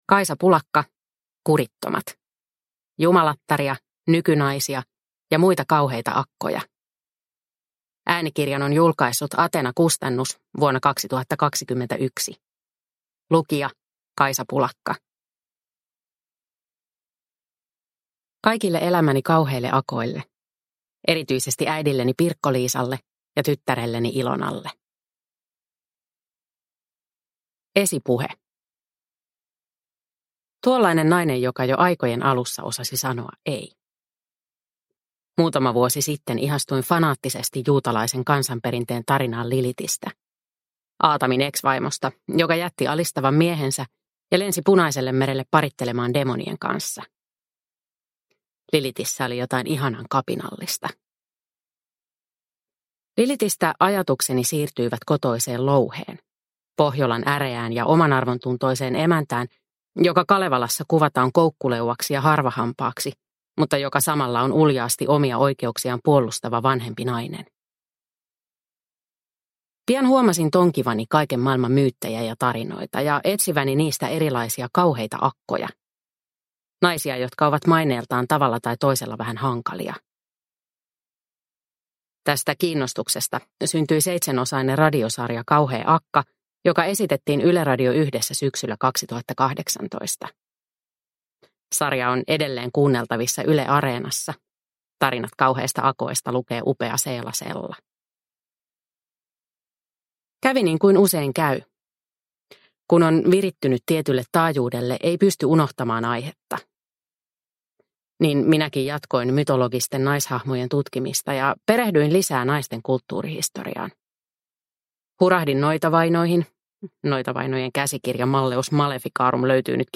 Kurittomat – Ljudbok – Laddas ner